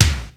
Index of /90_sSampleCDs/300 Drum Machines/Akai XR-10/Bassdrums
Bassdrum-09.wav